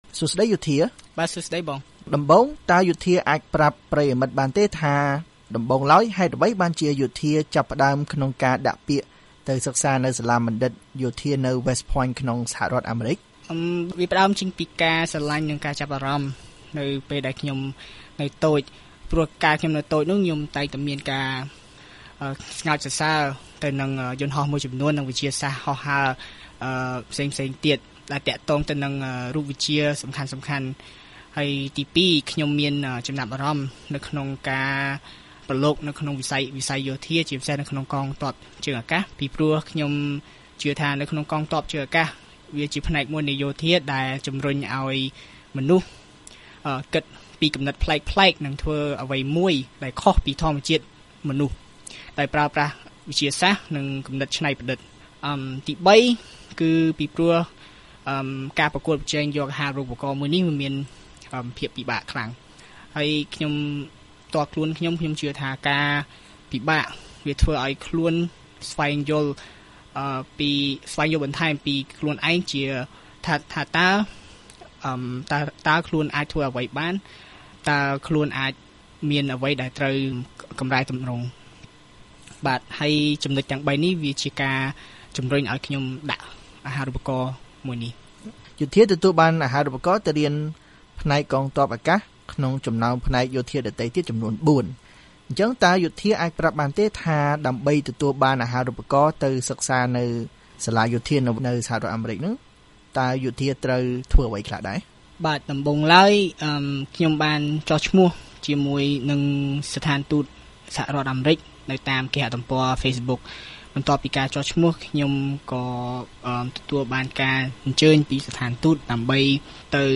បទសម្ភាសន៍ VOA៖ យុវជនកម្ពុជាទទួលបានអាហារូបករណ៍ទៅសិក្សានៅសាលាបណ្ឌិតសភាយោធាអាមេរិក